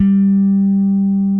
G3 1 F.BASS.wav